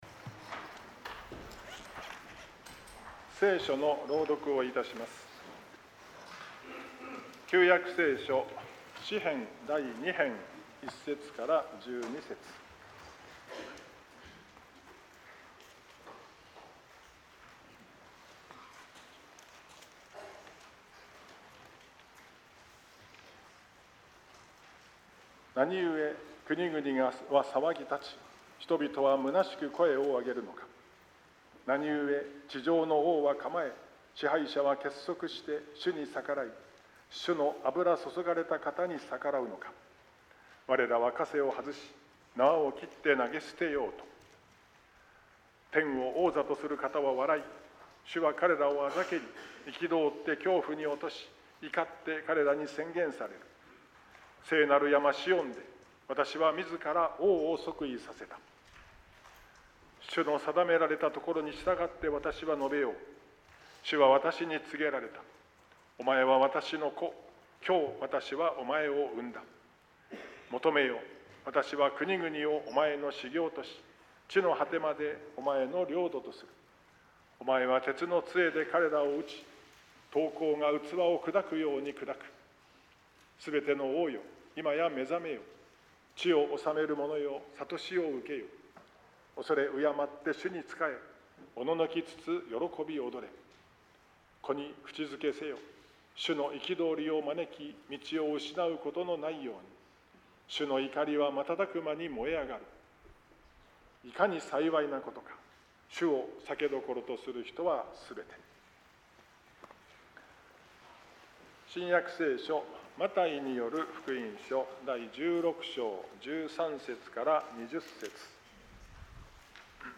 説教題「天の国の鍵」